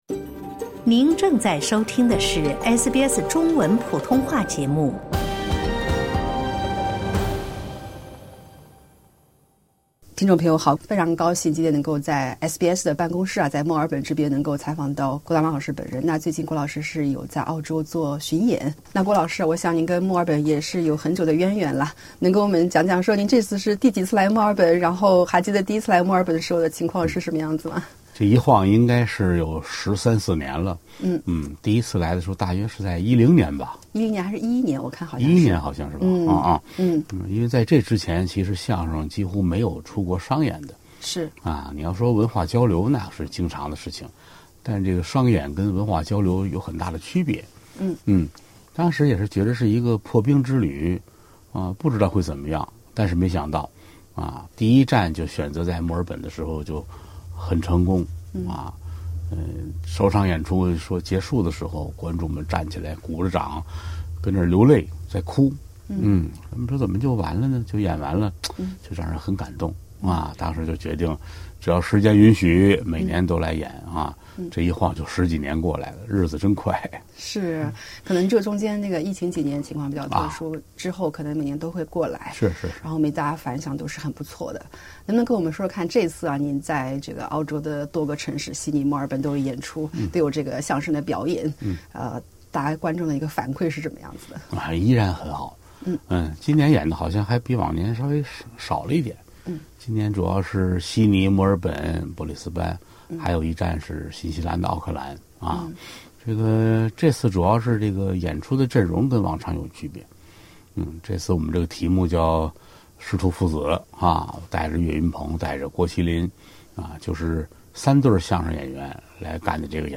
近日刚在澳大利亚、新西兰结束师徒父子相声大典的中国相声艺术家郭德纲在墨尔本接受SBS专访（点击播放键，收听完整采访）。